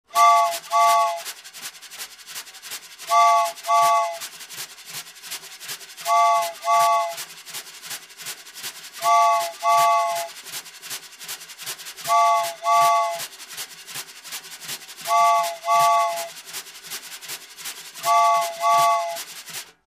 Звуки гудков паровоза